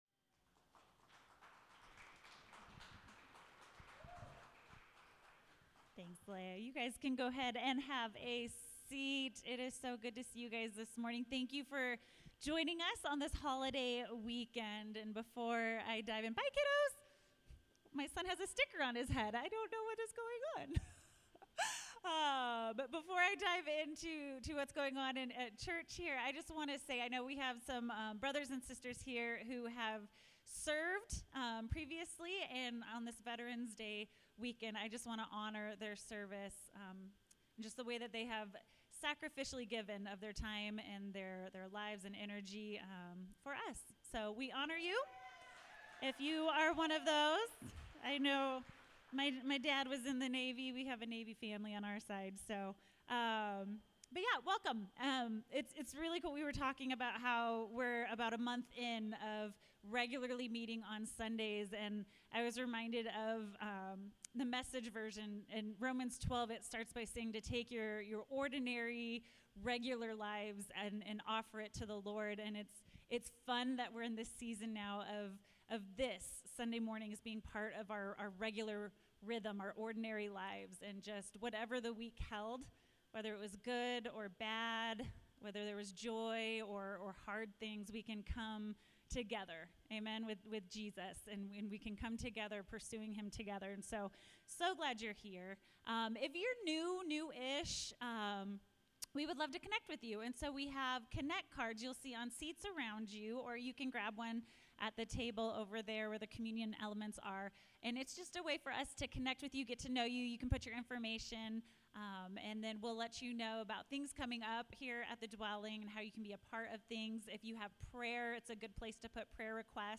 Anchored: What defines Us | Sermon Series